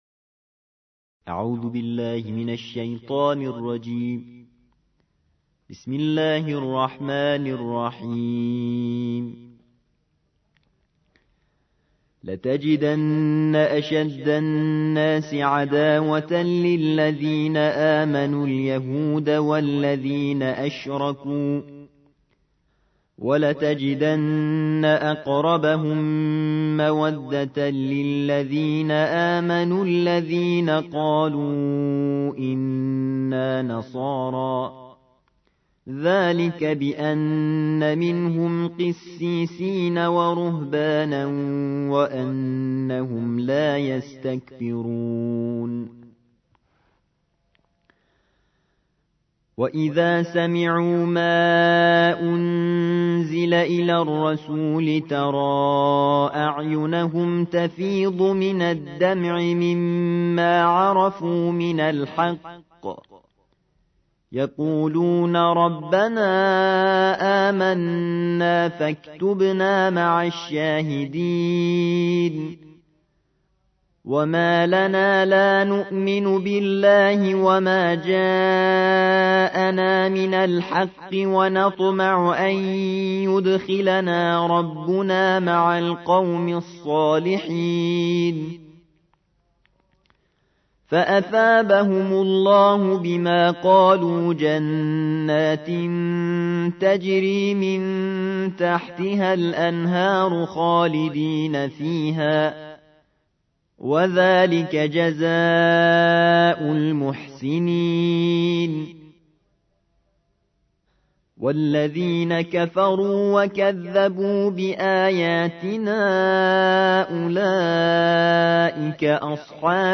الجزء السابع / القارئ